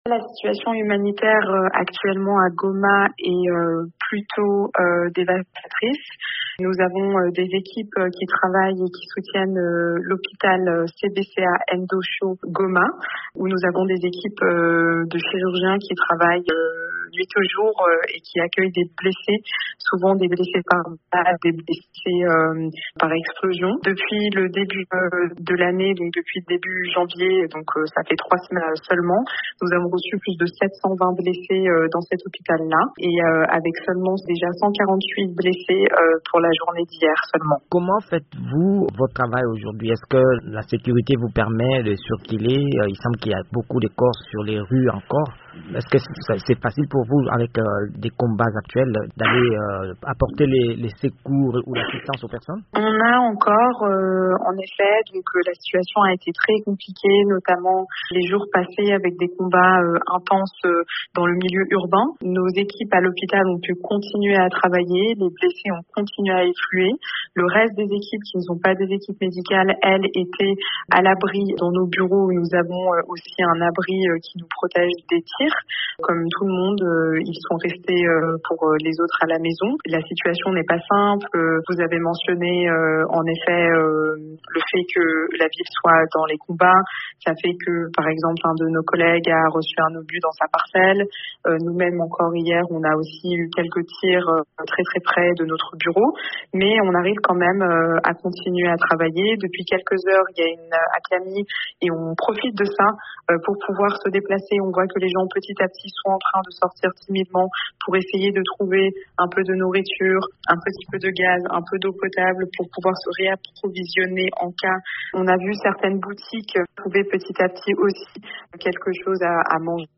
Le CICR face à la crise humanitaire à Goma : Témoignage